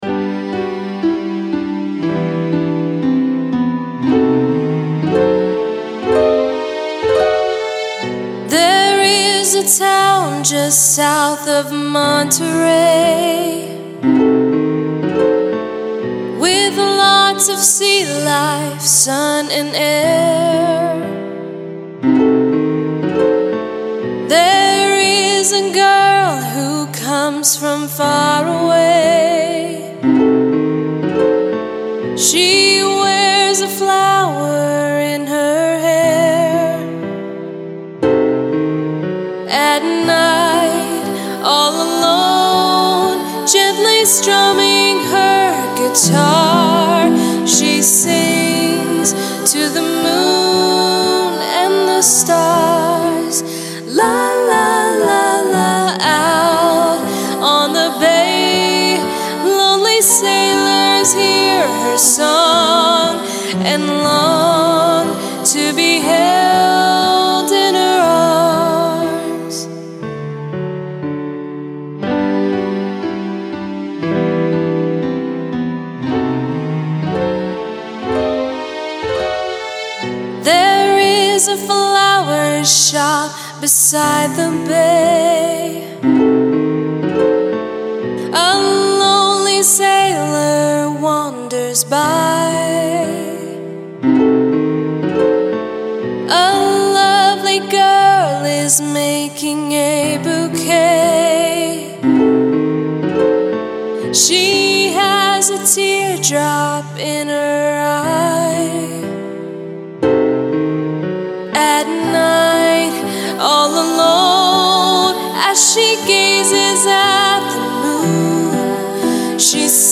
sung by a female lead